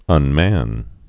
(ŭn-măn)